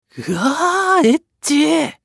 男性
熱血系ボイス～恋愛系ボイス～